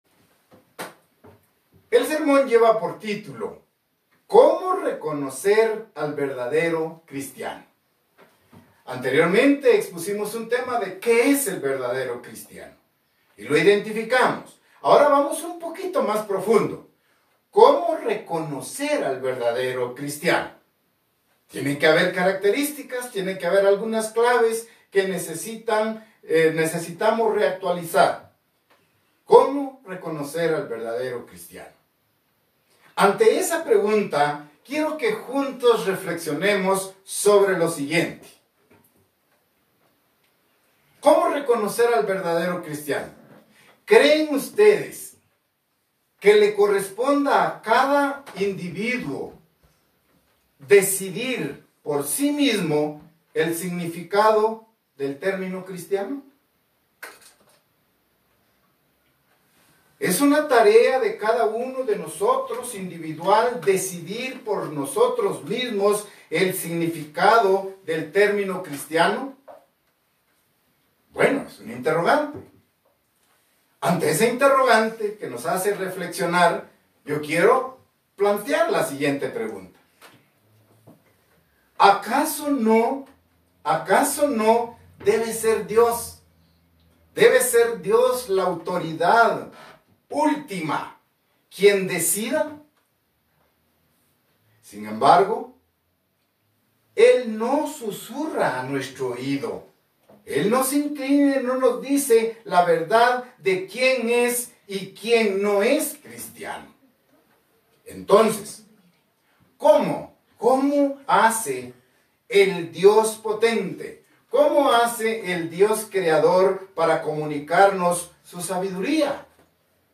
Mensaje entregado el 16 de mayo de 2020.